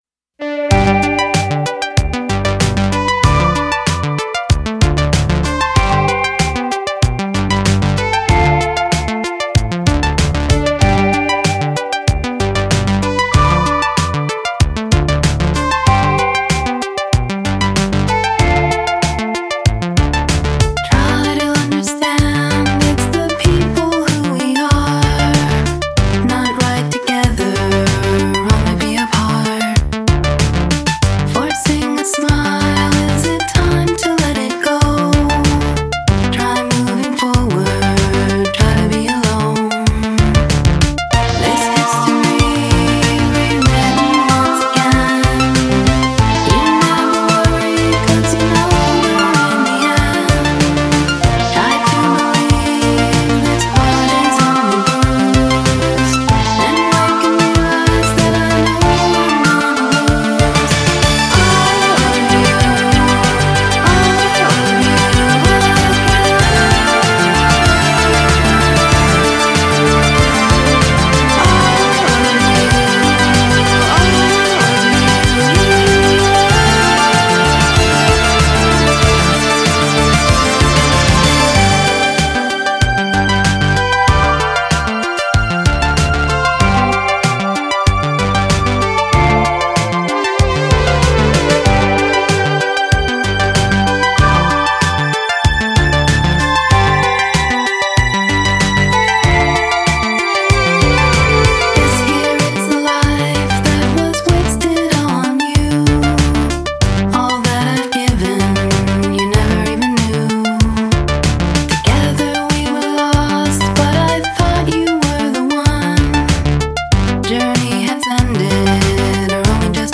synth-based pop duo
Recording with synths from the early 1980’s
natural-sounding pop songs